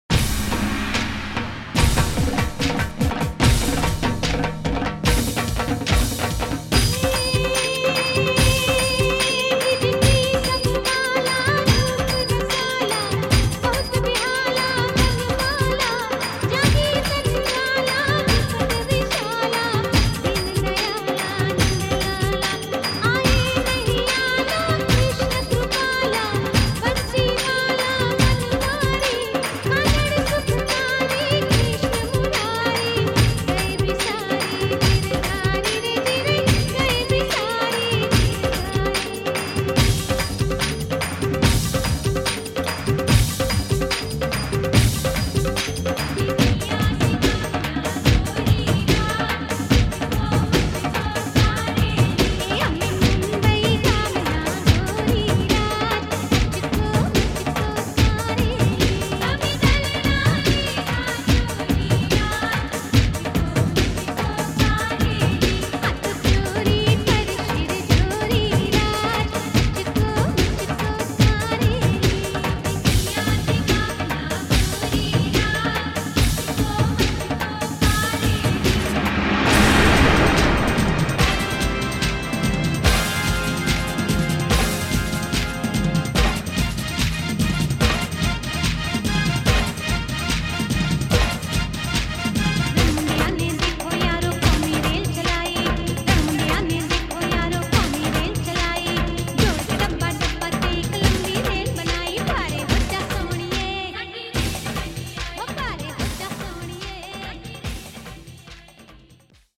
Navratri Special Dandiya